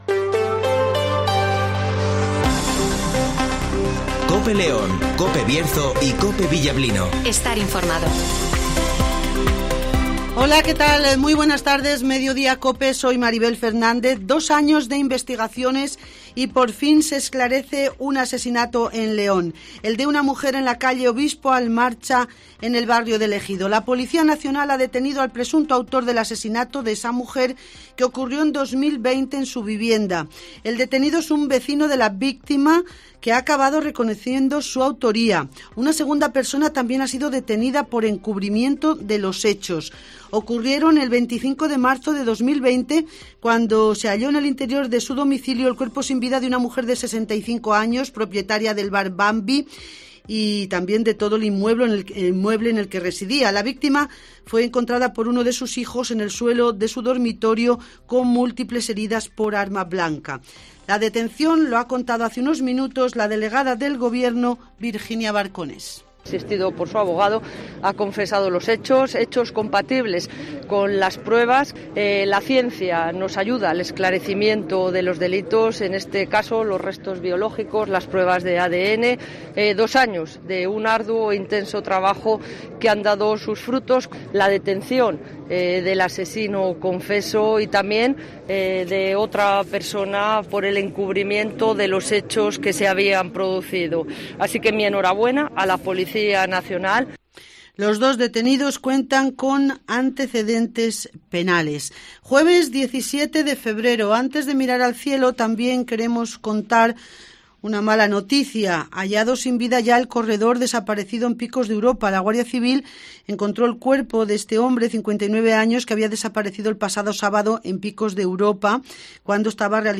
- Virginia Barcones ( Delegada del Gobierno )
- José Antonio Díez ( Alcalde de León )